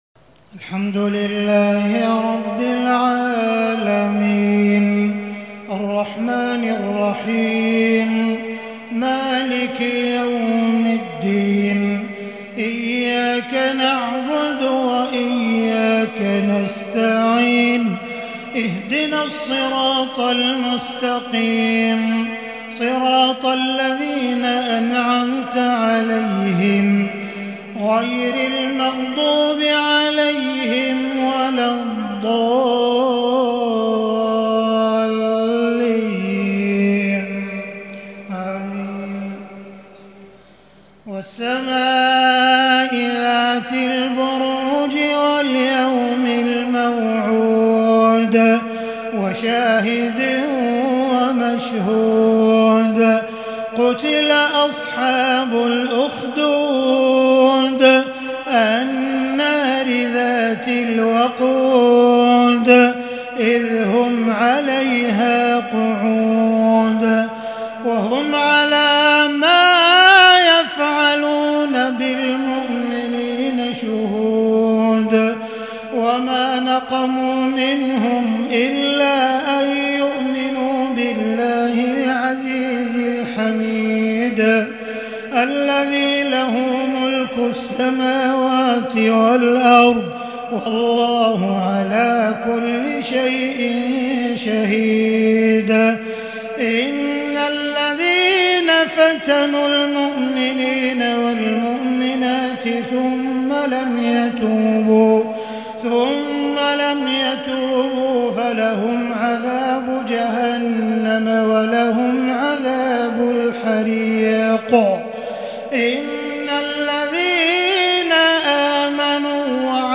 صلاة العشاء ١٤٤٢/٣/٨هــ من سورة البروج isha prayer from surah Al-buruj 25/10/2020 > 1442 🕋 > الفروض - تلاوات الحرمين